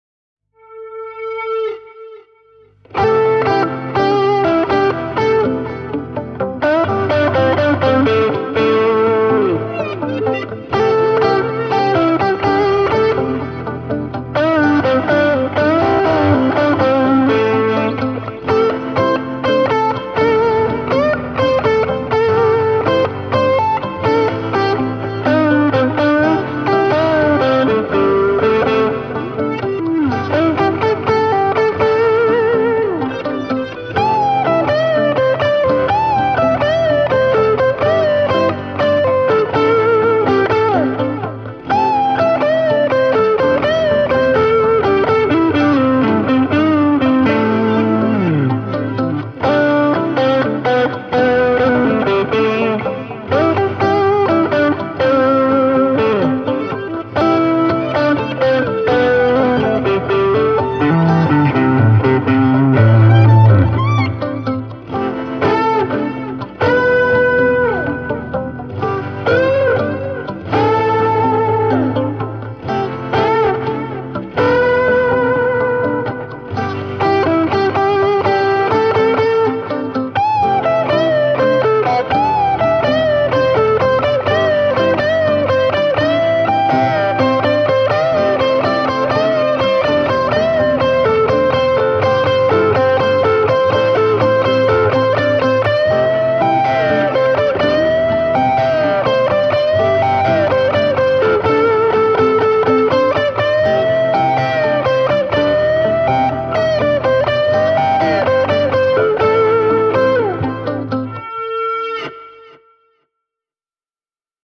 Tässä lyhyt klippi muutamasta säröpatchista: